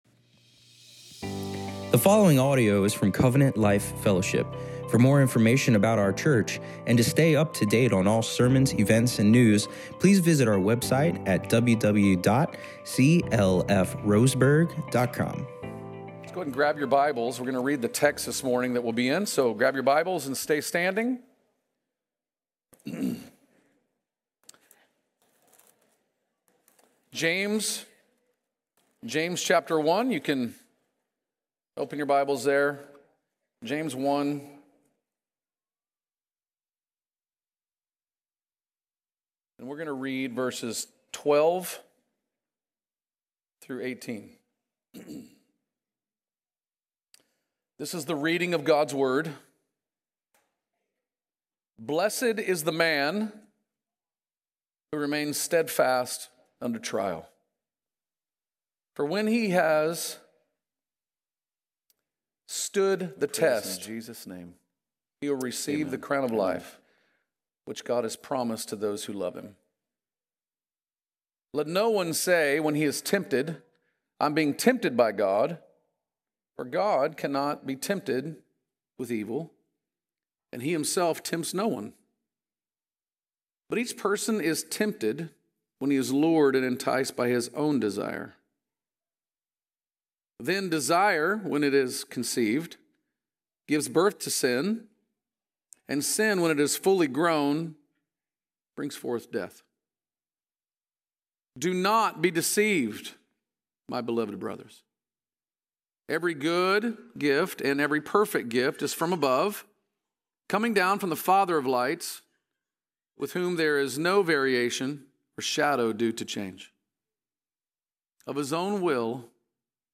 In this sermon, we dive into James 1:12-18 to explore how God’s goodness helps us navigate trials and resist the pull of temptation.